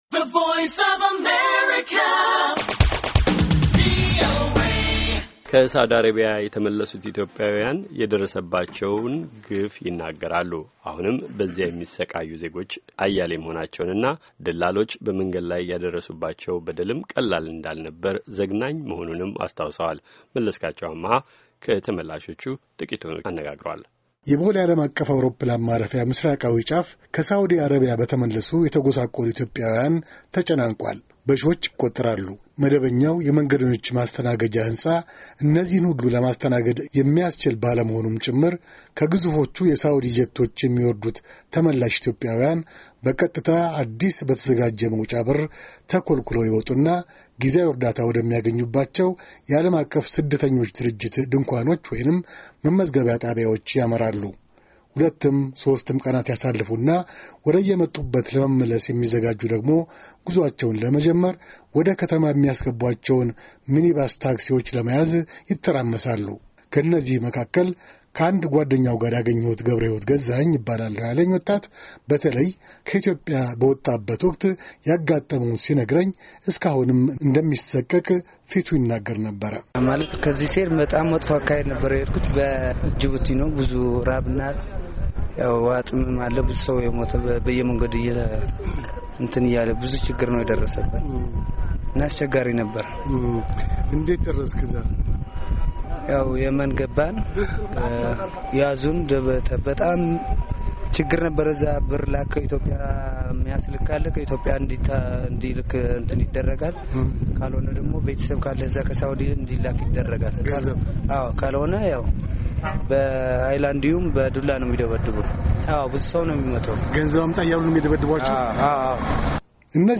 Ethiopian returnees from Saudi - vox-pop